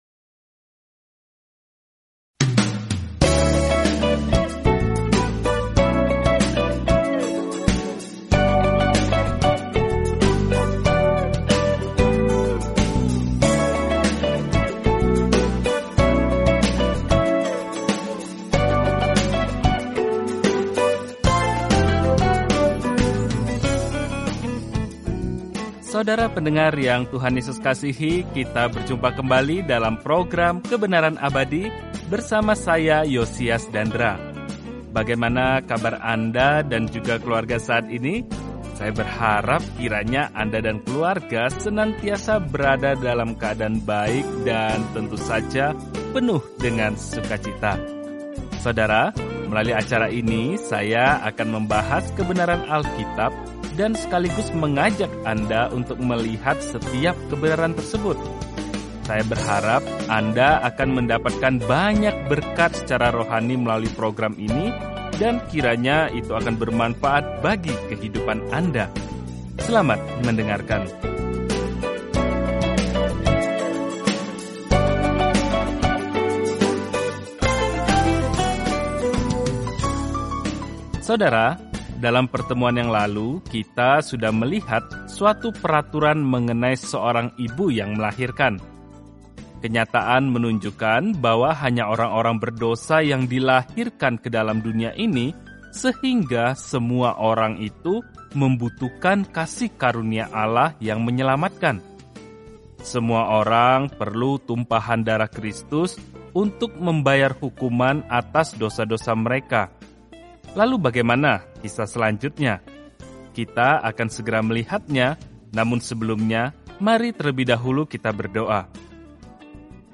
Dalam ibadah, pengorbanan, dan rasa hormat, Imamat menjawab pertanyaan itu bagi Israel zaman dahulu. Jelajahi Imamat setiap hari sambil mendengarkan studi audio dan membaca ayat-ayat tertentu dari firman Tuhan.